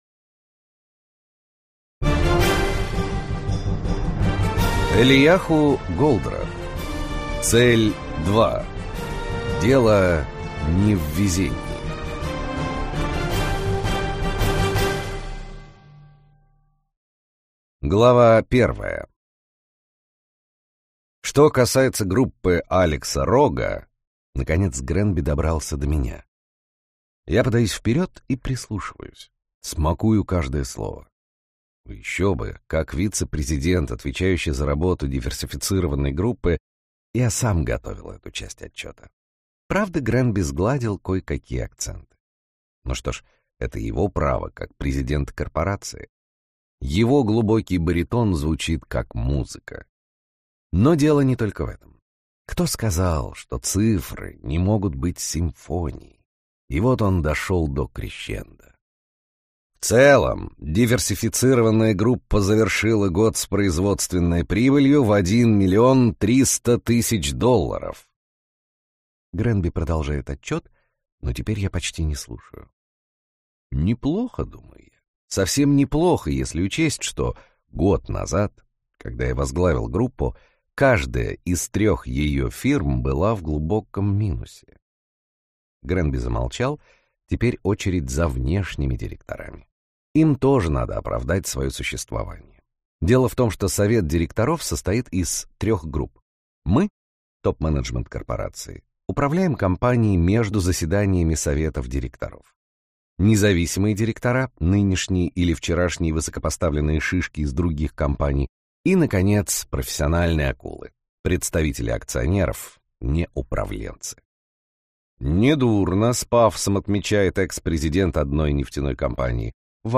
Аудиокнига Цель-2. Дело не в везении - купить, скачать и слушать онлайн | КнигоПоиск